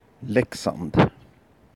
Leksand (Swedish pronunciation: [ˈlɛ̂kːsand]